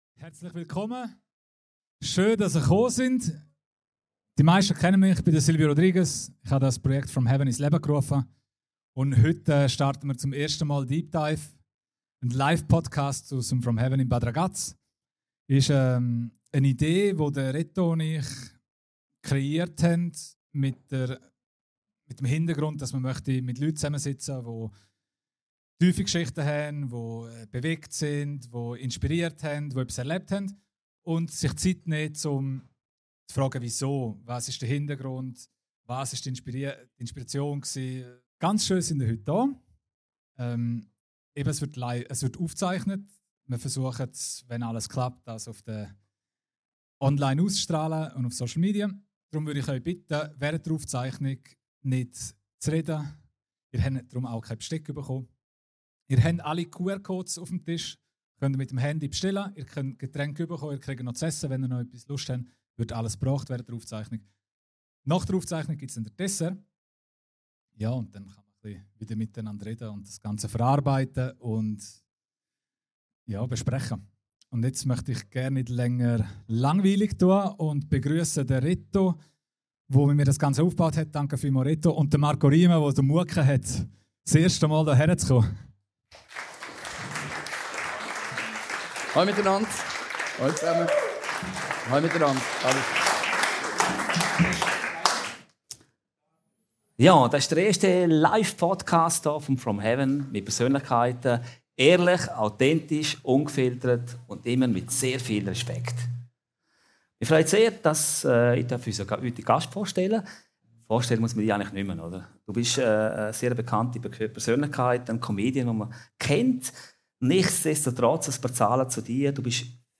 Aufgezeichnet live im From Heaven, Bad Ragaz.